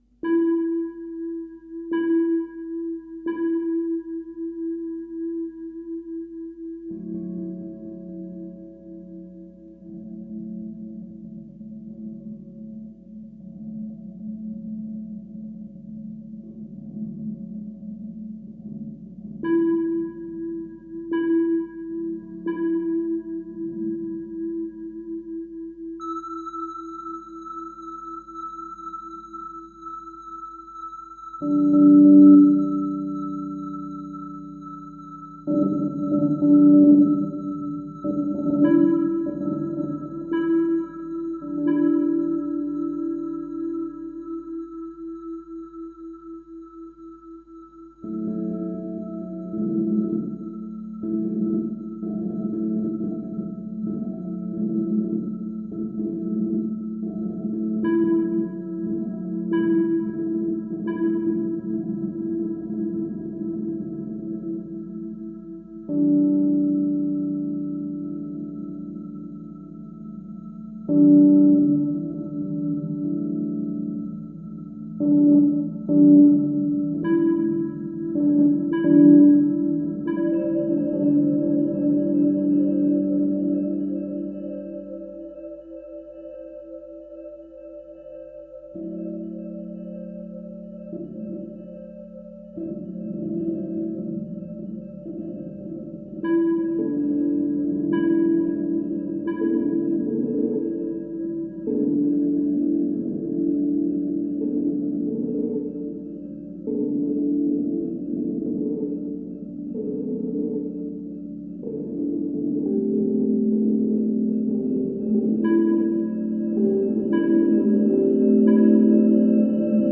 A first taste of our duo playing improvised music. On a three notes riff, sounds comes from away and disappear like visions in the cold landscape of a frozen lake. The piece is proposed as we improvised it some days ago. No further elaboration.
electric guitar, various effect processing
Max/MSP laptop Il Ghiaccio Sottile della Tua Fragile Mente Back